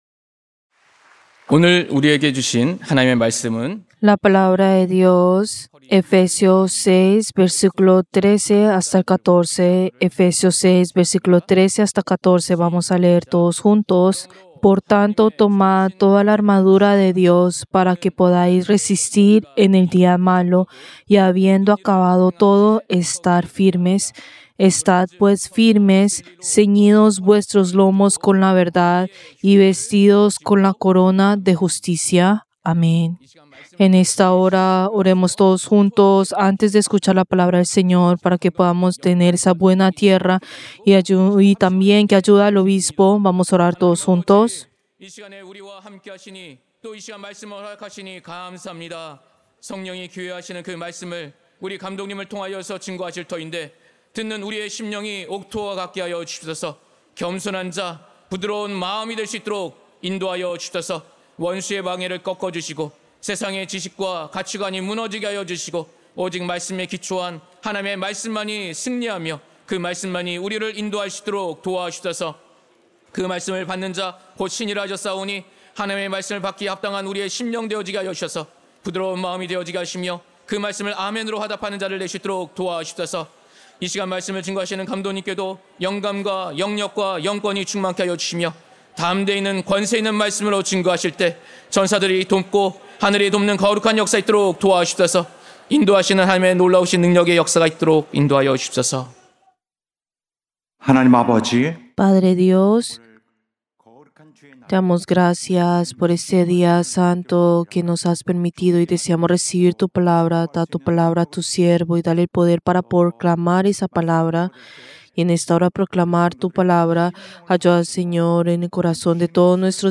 Servicio del Día del Señor del 29 de junio del 2025